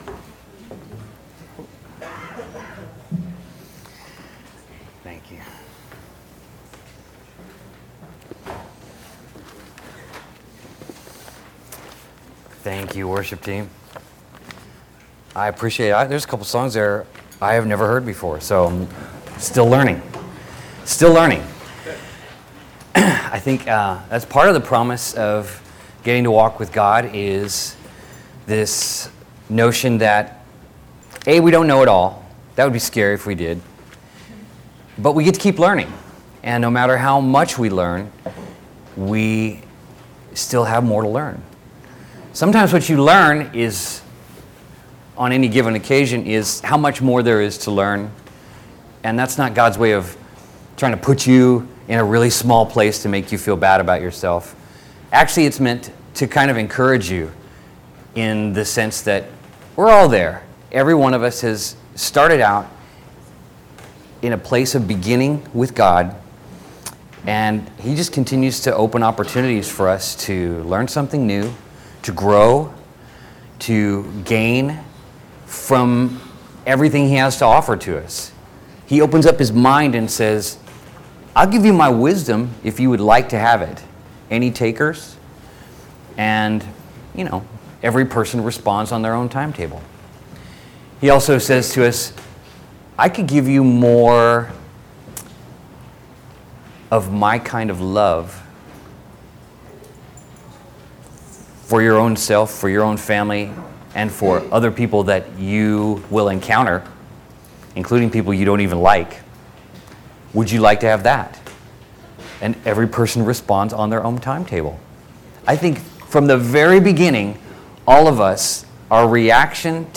Sermon October 15, 2017